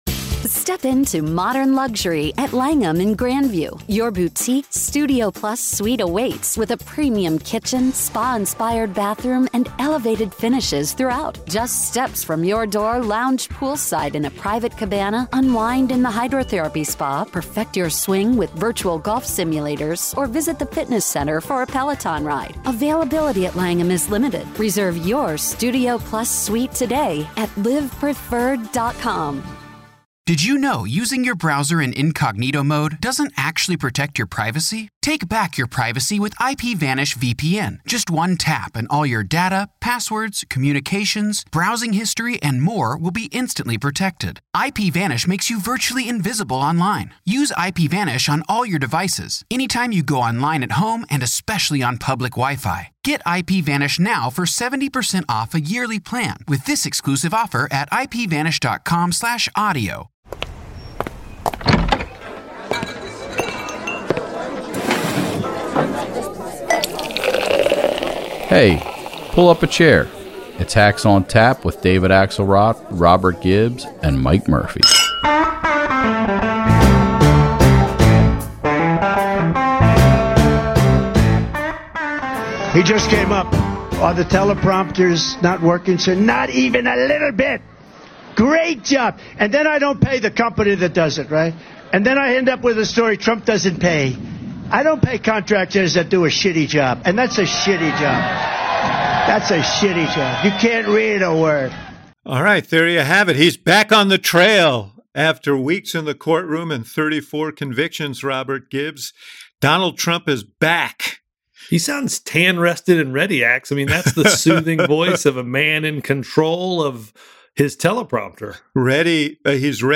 Headliner Embed Embed code See more options Share Facebook X Subscribe This week Axe and Gibbs were joined by focus group guru and Bulwark legend, Sarah Longwell. Trump is out of the courtroom and back on the trail, the Hacks break down his concerns over teleprompter technology, Hunter Biden’s conviction, how both camps are preparing for the debate, vibe sessions v. recessions, dancing bears, and so much more!